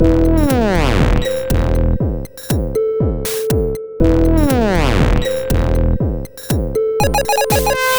/samples/CHIPSHOP_DELUXE/CHIPSHOP_LOOPS/120_BPM/
ChipShop_120_Combo_E_04.wav